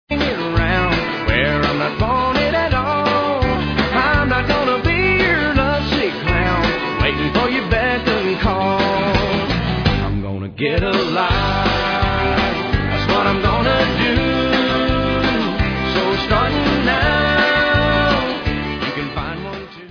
sledovat novinky v kategorii Country